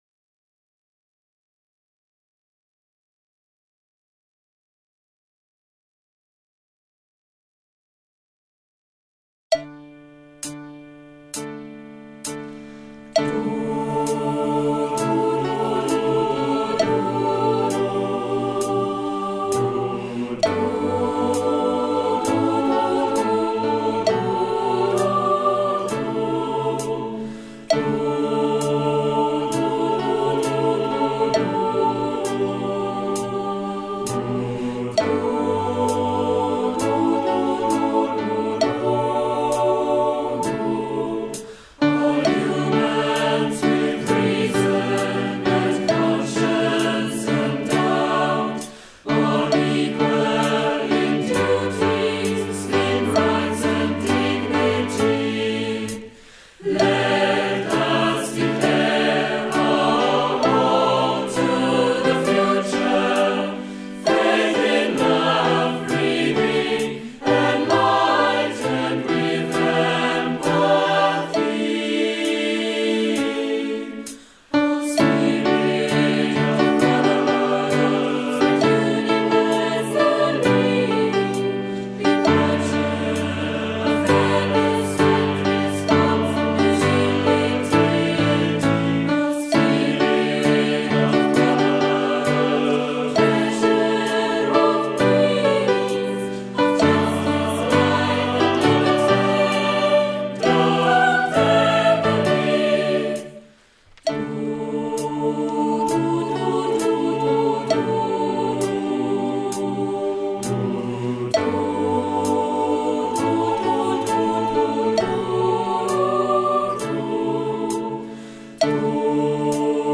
Tutti mit Klick